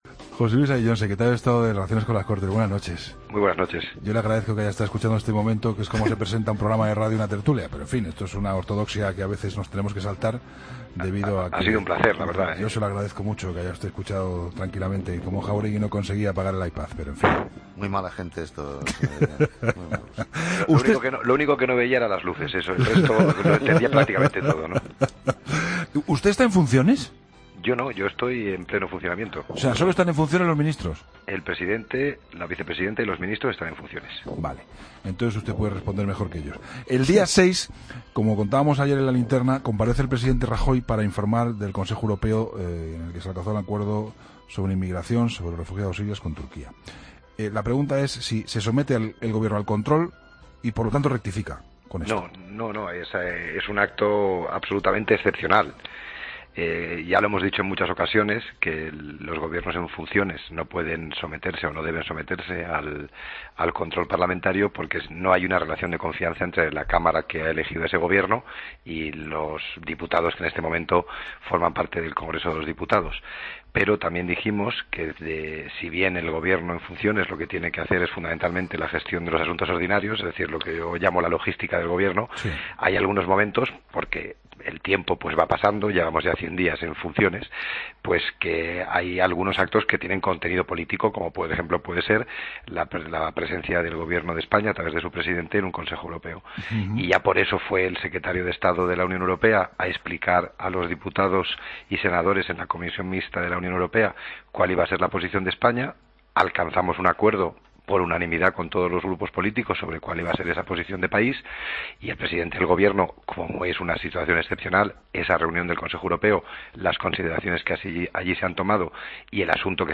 AUDIO: Escucha la entrevista a José Luis Ayllón, secretario de Estado de Relaciones con las Cortes en 'La Linterna'